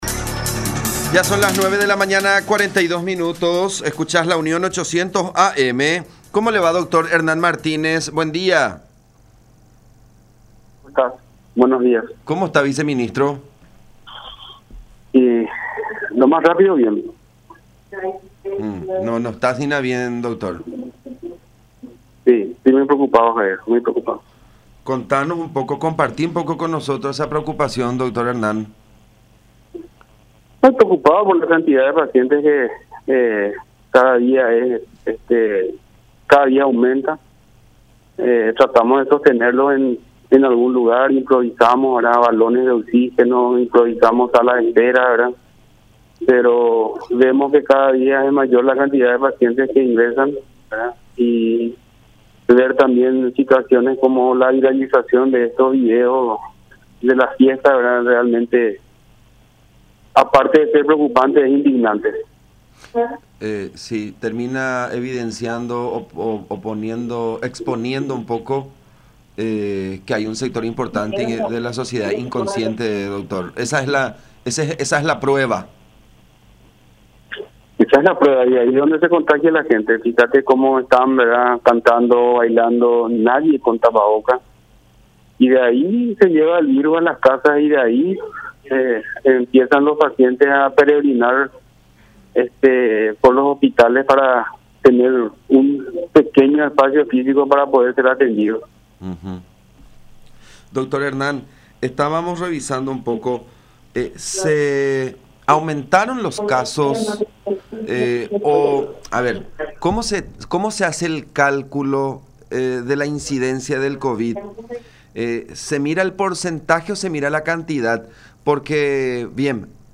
Tiene que estar aislado esperando su resultado”, dijo Martínez en conversación con La Unión, subrayando que el testeo debe hacerse al quinto día de presentar síntomas.